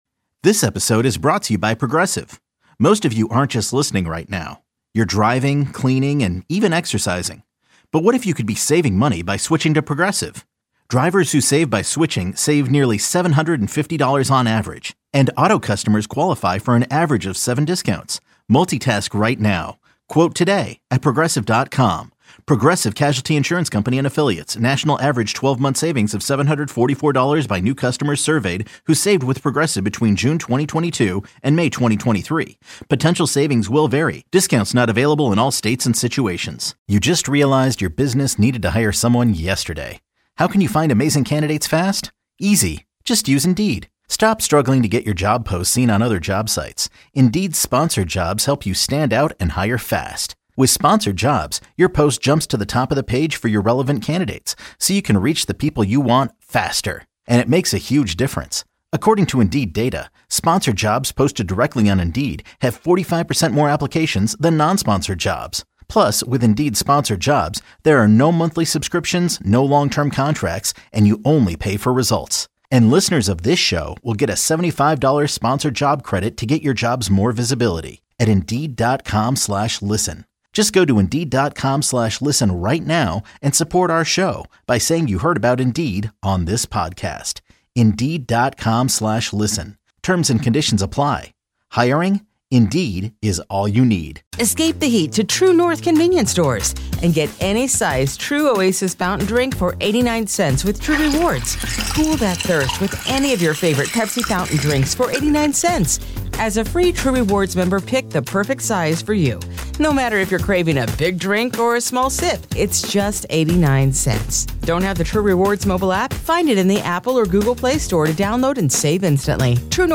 one-on-one interviews